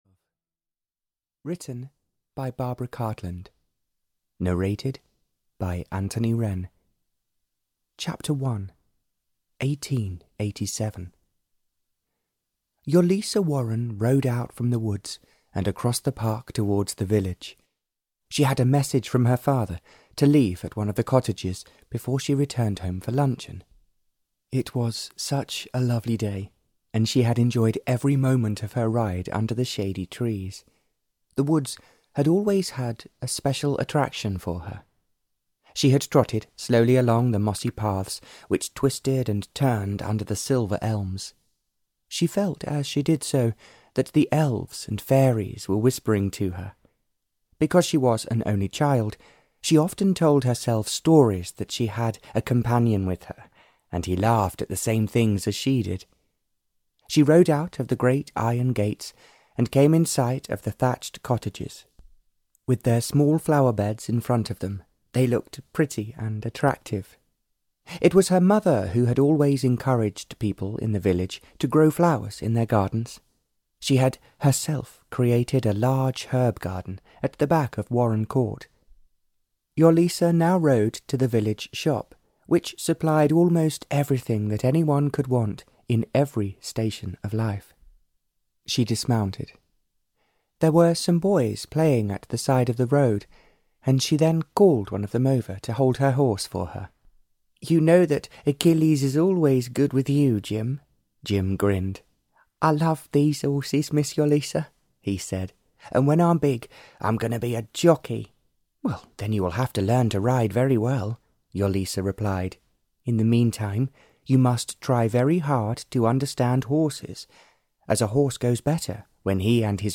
Audio knihaOne Minute to Love (EN)
Ukázka z knihy